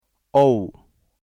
ただ口語では，aiは「éy」「é」, auは「ow」「o」と発音されることも多いです。
au(ow)